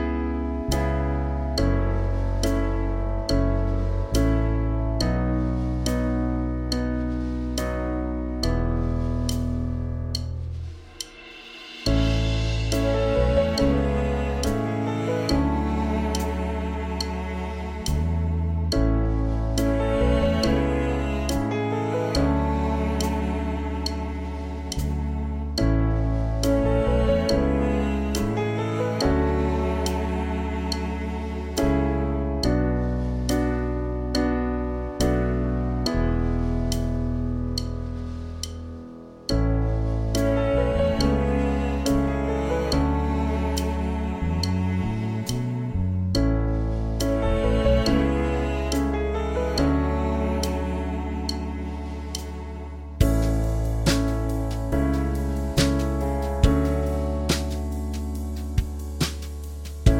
Minus Main Guitars For Guitarists 4:45 Buy £1.50